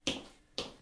手机剧烈震动
描述：电话在桌上震动
标签： 振动 表面坚硬 手机
声道立体声